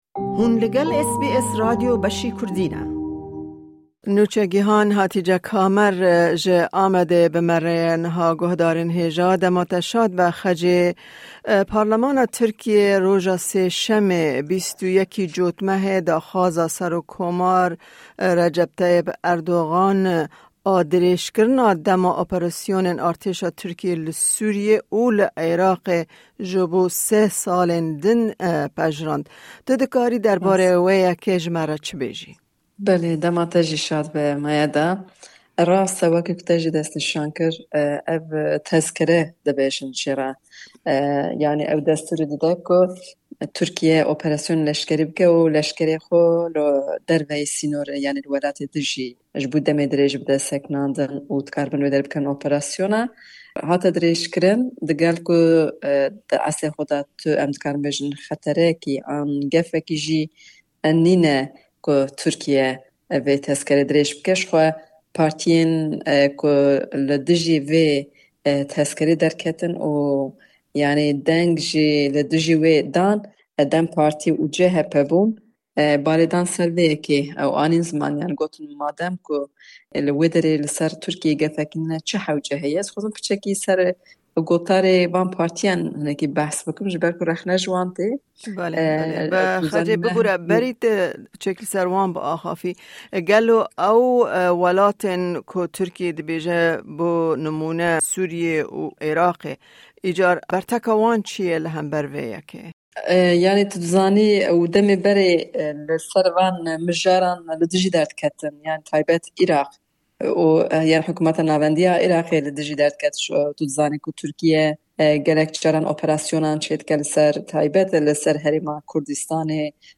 Zêdetir derbarê mijarên li jor di raporta ji Amedê de heye.